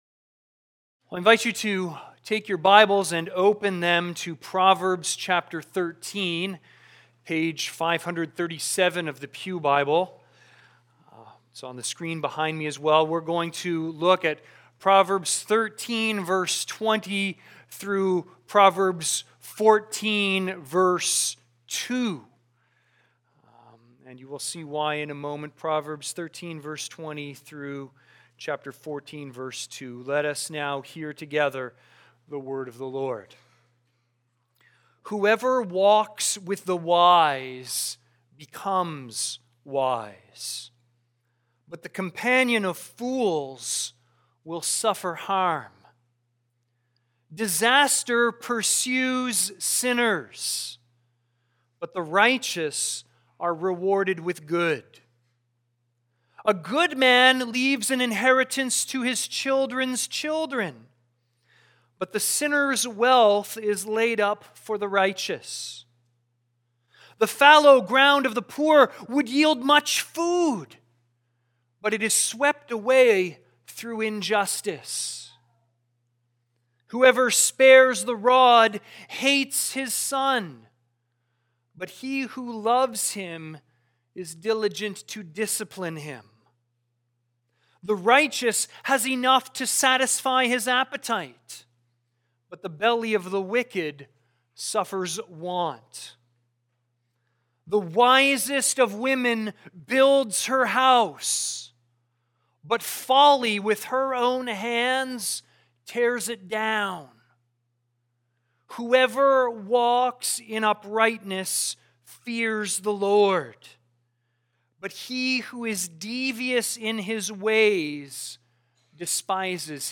Sermons | Campbell Baptist Church
View the Sunday service. cbcwindsor · 2023-02-12 Sunday Service